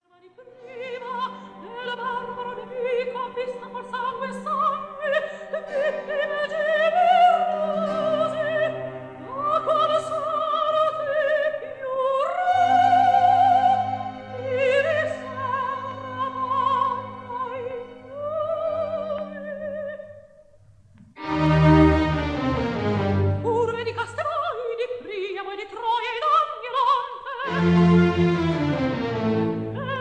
(soprano)
Recorded in Abbey Road Studio No. 1, London